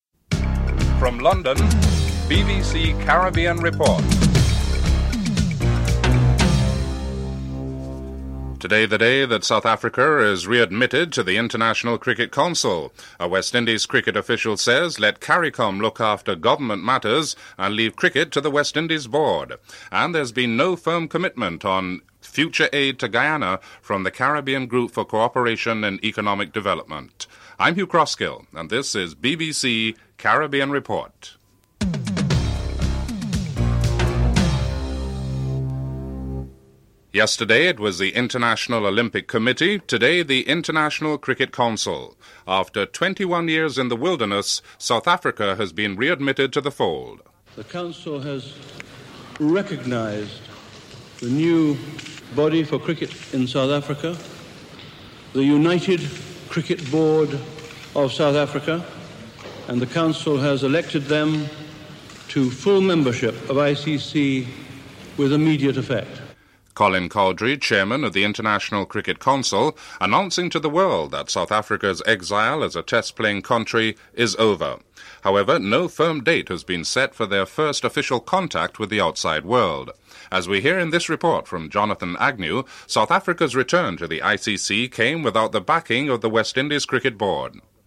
1. Headlines (00:00-00:36)
Interview with Guyana’s Finance Minister, Carl Greenidge (08:08-11:14)